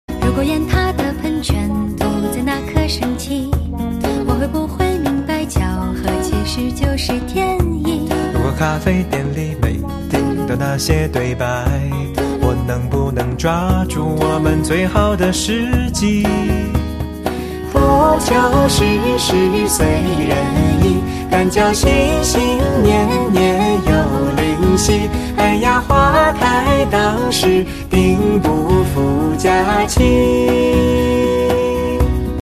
M4R铃声, MP3铃声, 华语歌曲 119 首发日期：2018-05-15 05:29 星期二